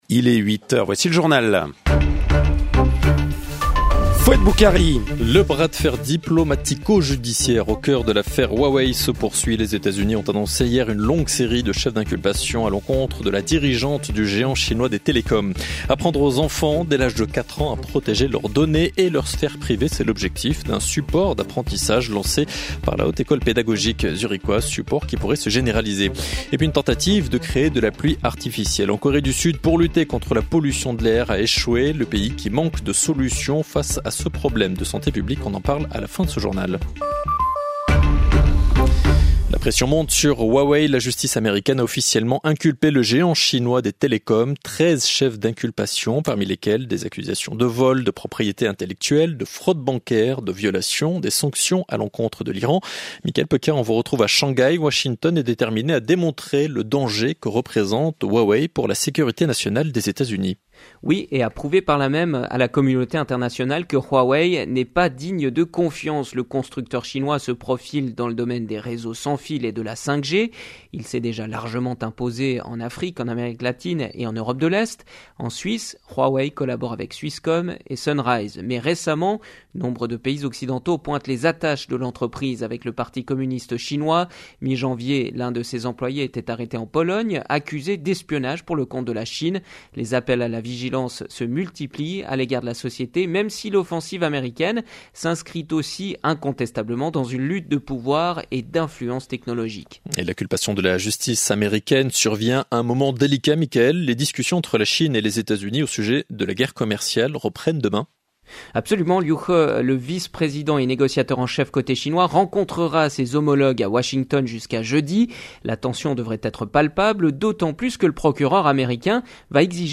Find the audio file (in French) here (interview starts at about 8 minutes from beginning)